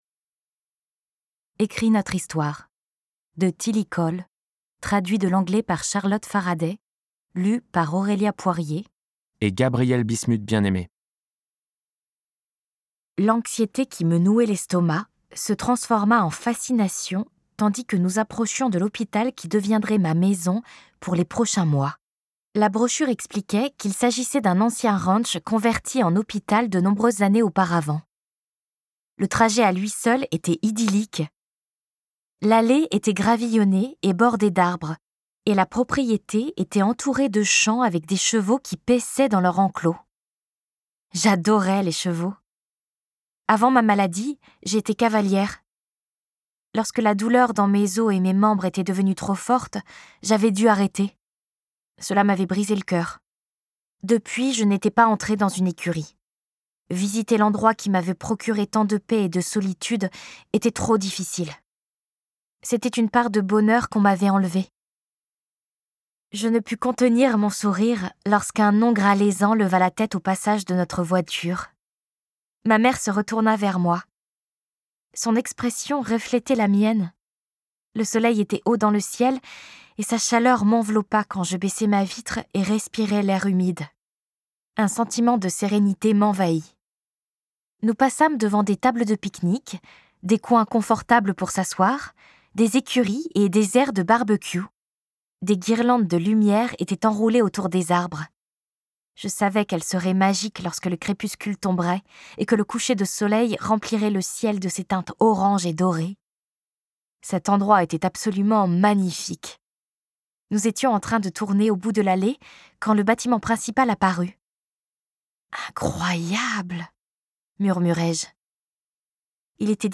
Diffusion distribution ebook et livre audio - Catalogue livres numériques
Interprétation humaine Durée : 08H58 22 , 95 € Ce livre est accessible aux handicaps Voir les informations d'accessibilité